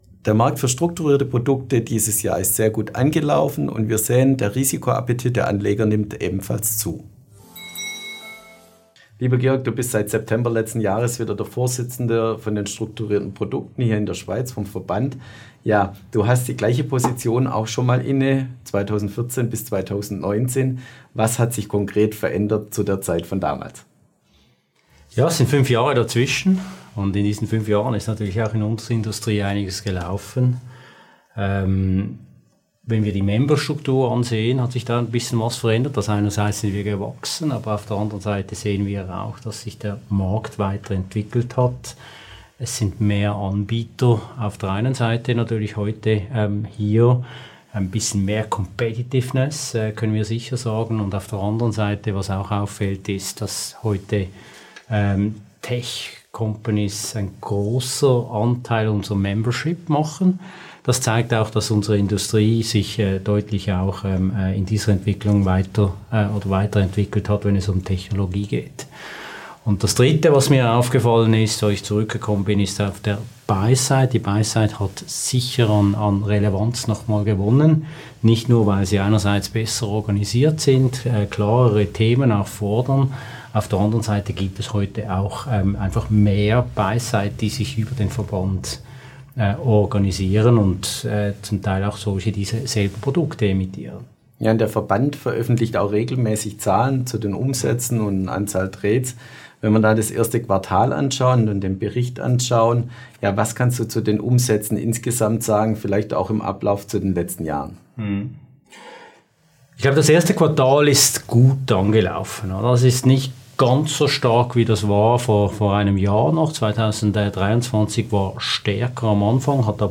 Experteninterview